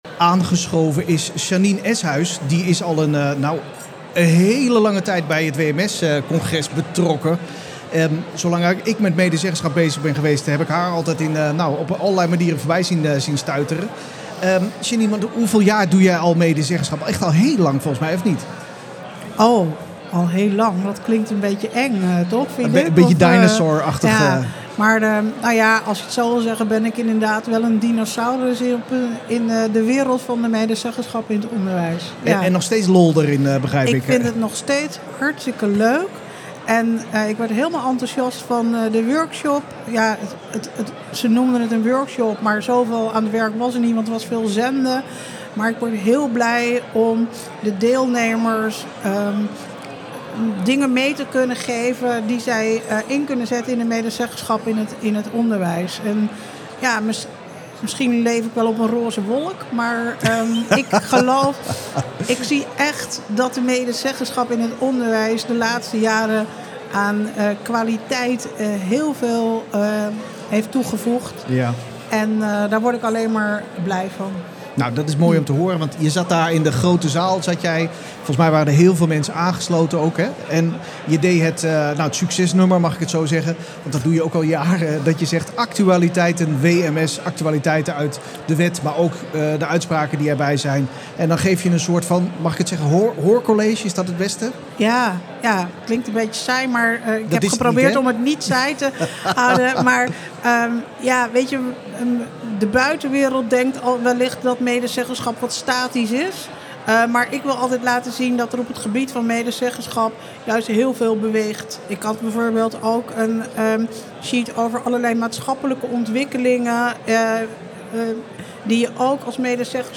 In de live-podcast What Next, die tijdens het congres werd opgenomen